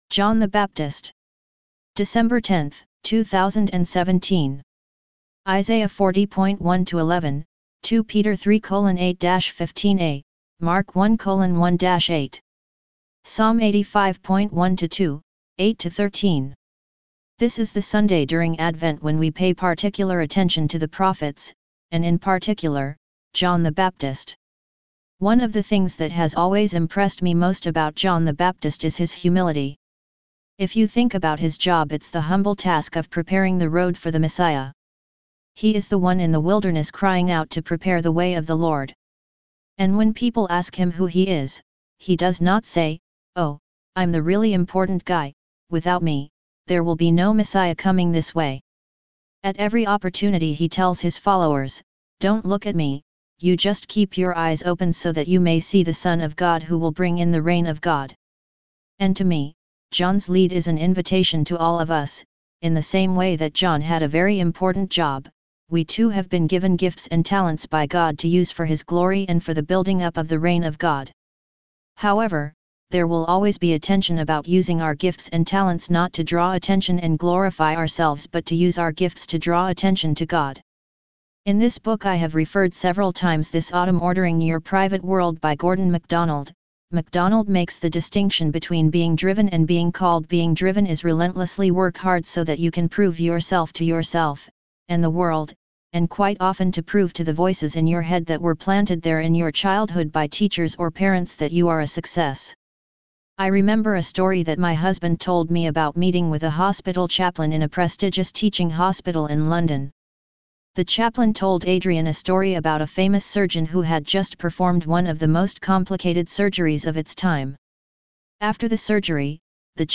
Sermon-John-the-Baptist-Using-our-Gifts-proofed.mp3